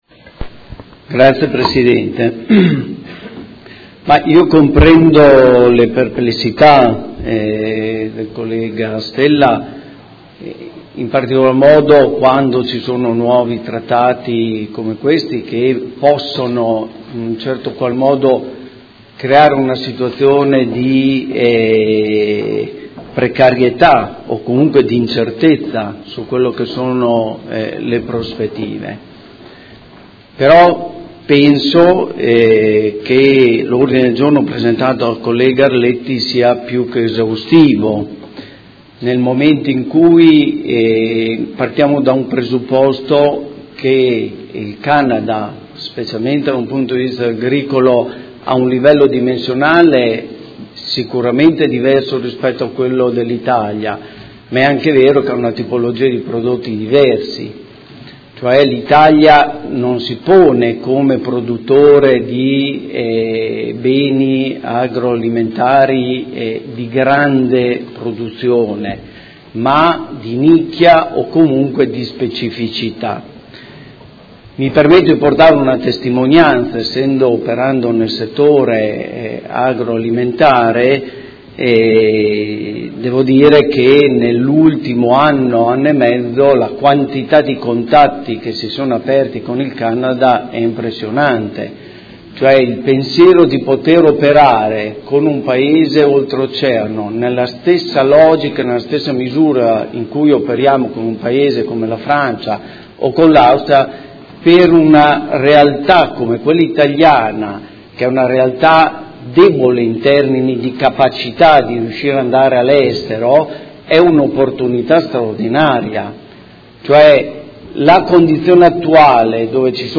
Antonio Montanini — Sito Audio Consiglio Comunale
Seduta del 15/02/2018. Dibattito su Ordine del Giorno presentato dal Gruppo Consiliare Art.1-MDP/Per Me Modena avente per oggetto: A sostegno di un commercio libero e giusto e per un’Europa libera dal CETA, e Ordine del Giorno presentato dai Consiglieri Arletti, Lenzini, Venturelli, Forghieri, Pacchioni, Liotti, De Lillo, Fasano, Baracchi e Poggi (PD) avente per oggetto: Trattato di libero scambio UE/Canada: un’ occasione di sviluppo anche del territorio modenese